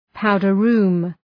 Προφορά
{‘paʋdərru:m}